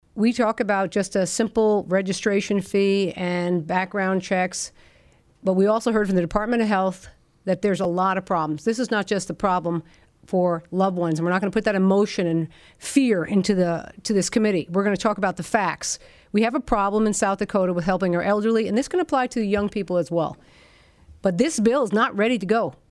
District 5 state Rep. Josephine Garcia, R-Watertown said the bill still wasn’t ready for passage…